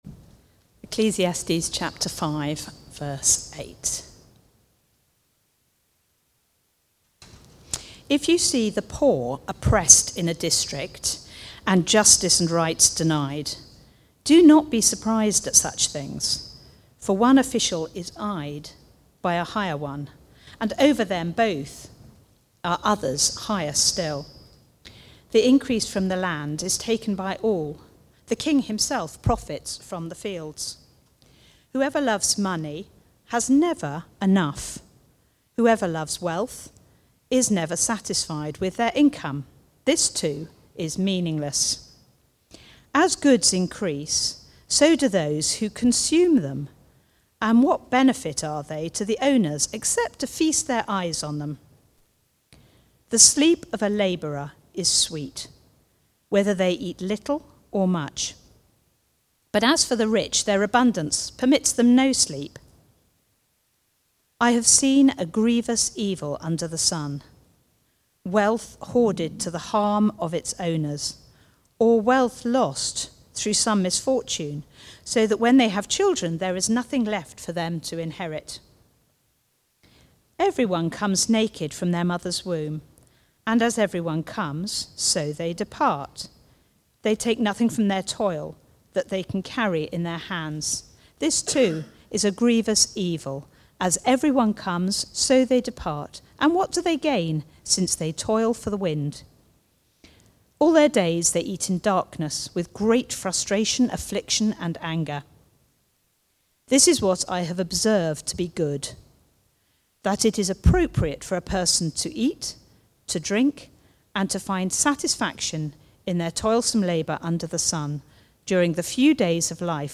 Preaching
Recorded at Woodstock Road Baptist Church on 12 October 2025.